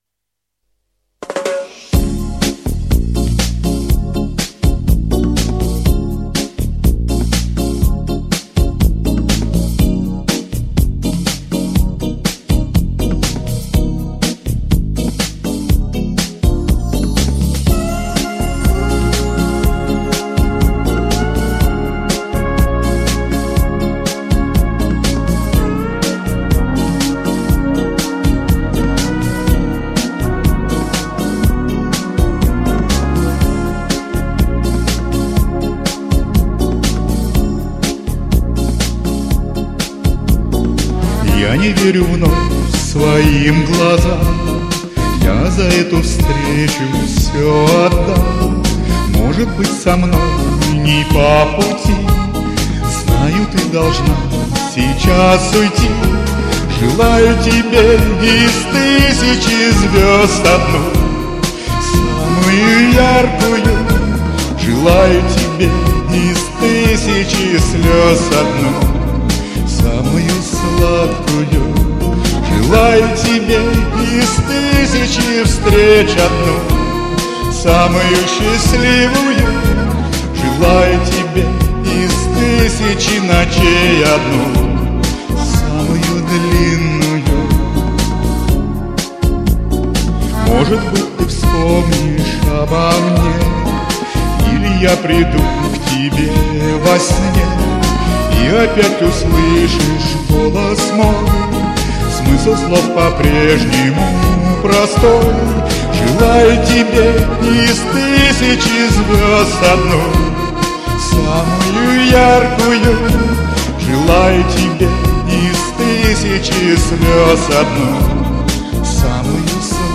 Эмоционально исполнили хорошие песни.